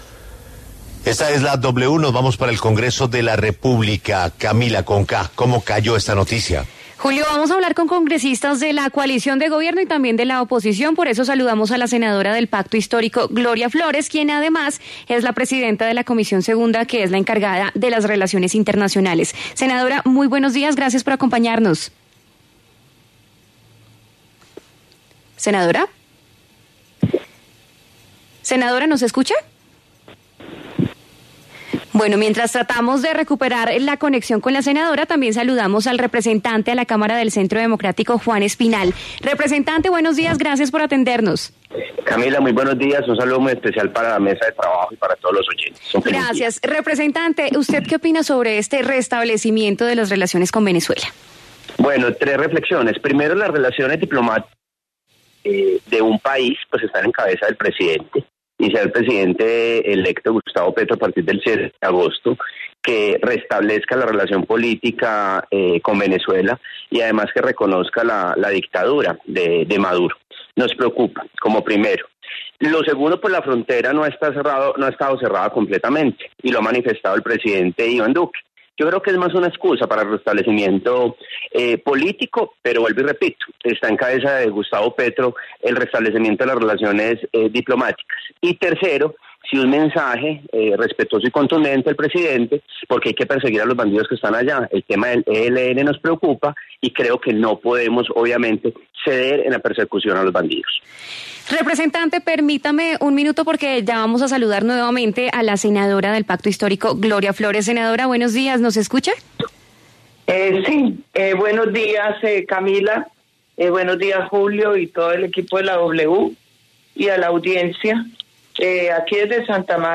La W conversó con los congresistas Gloria Flórez y Juan Espinal sobre la agenda de trabajo para la normalización gradual de las relaciones binacionales entre Colombia y Venezuela a partir del próximo 7 de agosto.